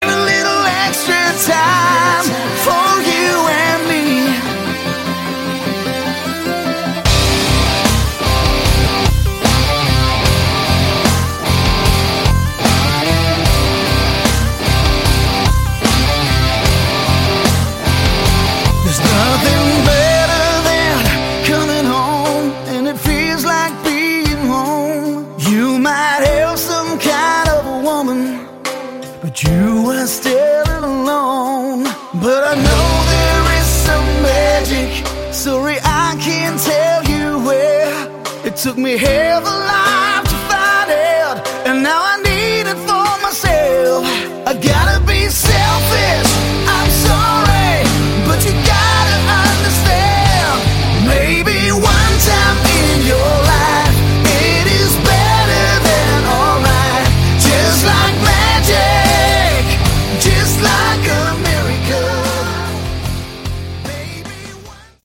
Category: AOR
drums, bass, keyboards, lead and acoustic guitar, vocals